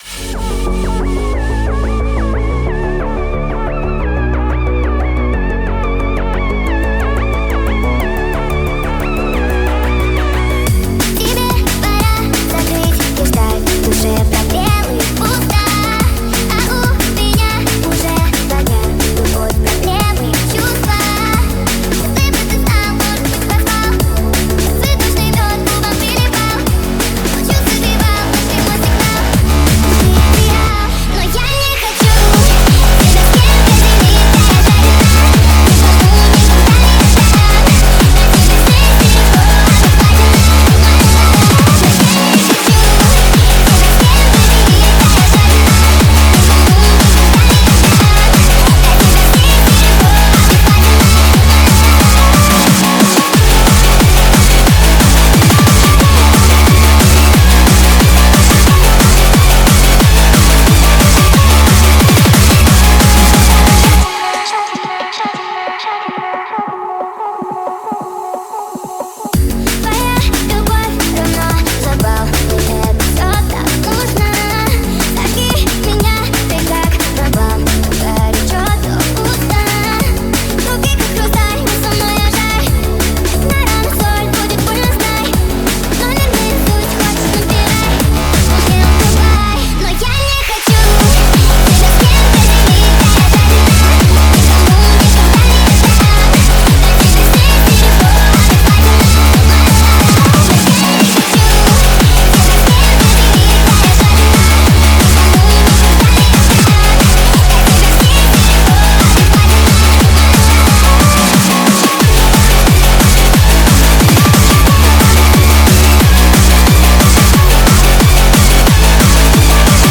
a hardstyle remix